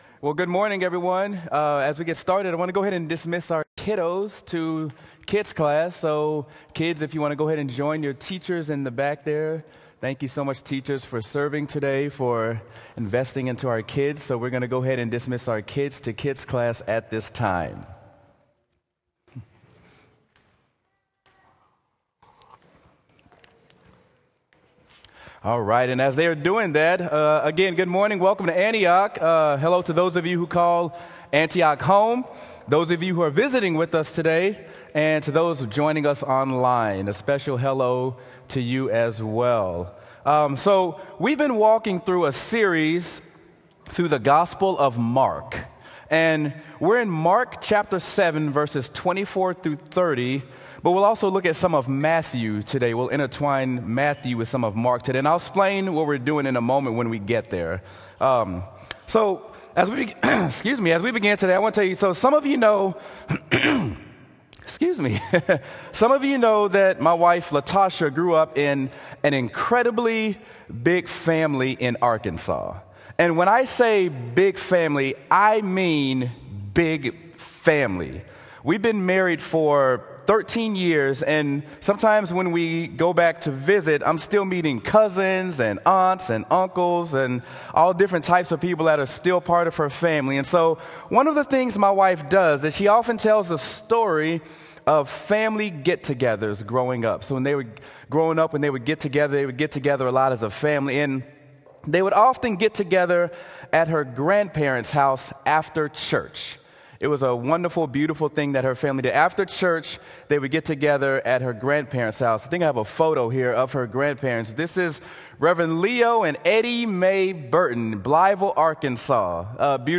Sermon: Acts: We Are the Church: What Does This Mean
sermon-acts-we-are-the-church-what-does-this-mean.m4a